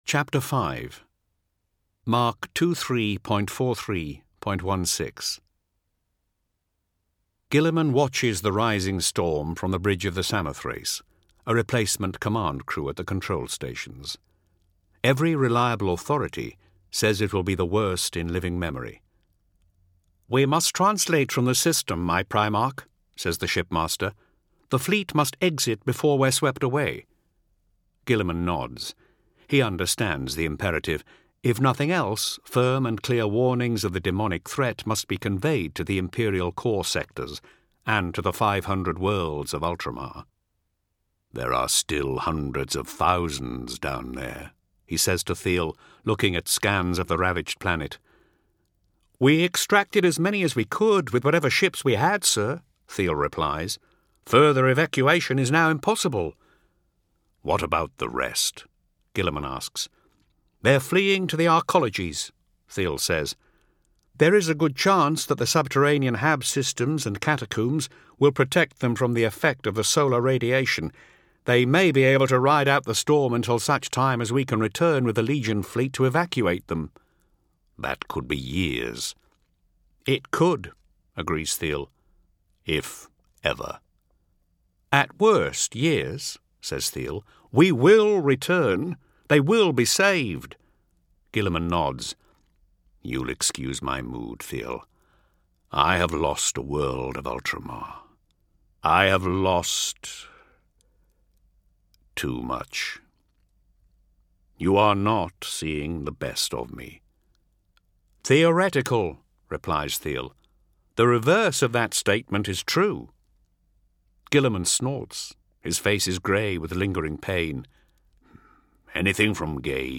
Games/MothTrove/Black Library/Horus Heresy/Audiobooks/The Complete Main Series/HH 11-20/19